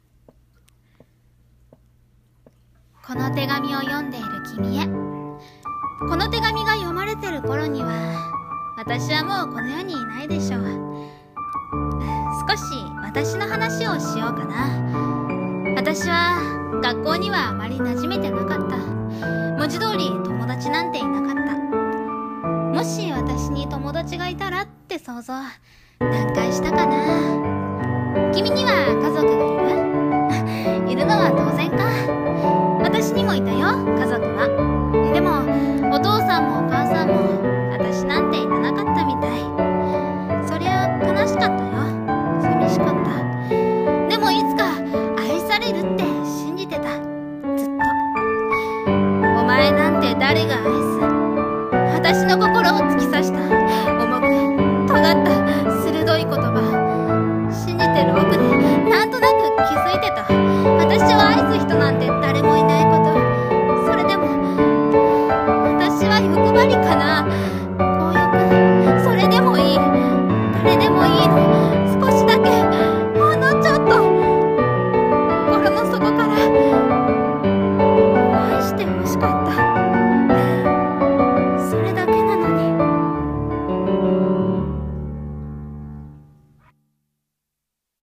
『愛されたかっただけなのに』 声劇台本